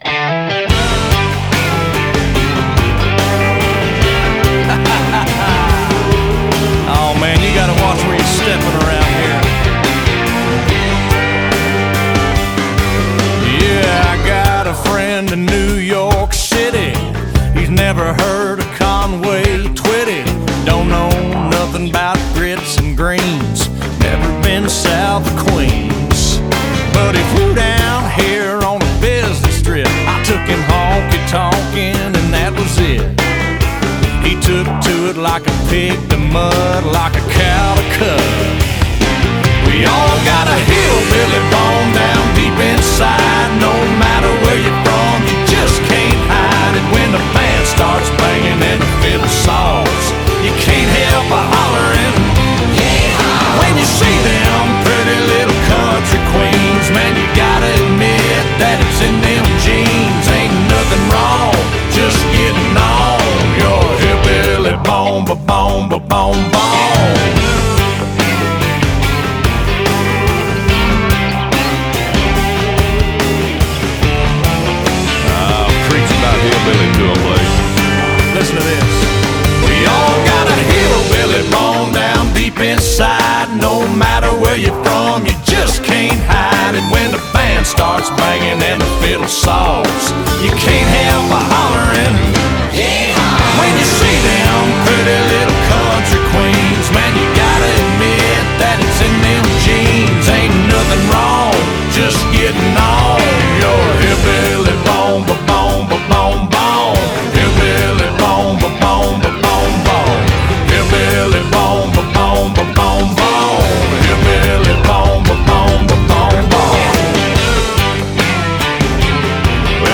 BPM144
MP3 QualityMusic Cut